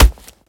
melee_hit_01.ogg